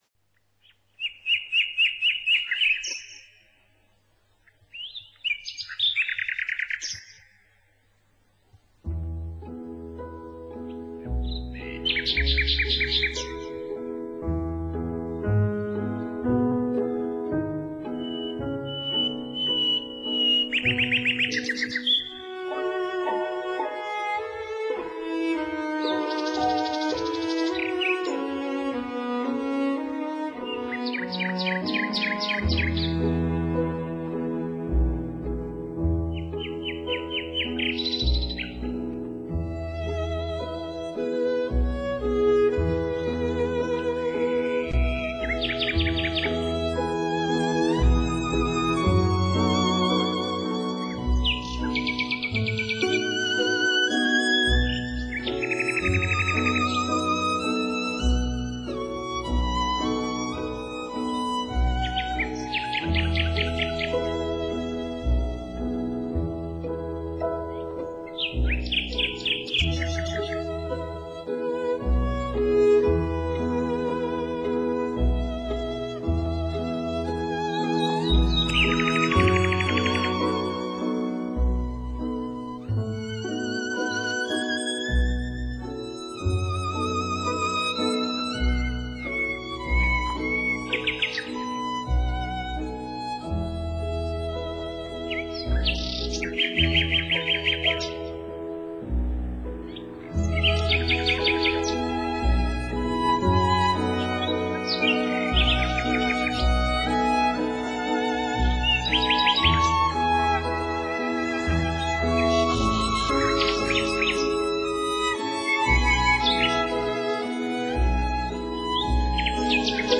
《叹息小夜曲》是一首哀叹失恋的优美歌曲， 缓慢、轻柔的旋律中流露着一丝苦恼之情。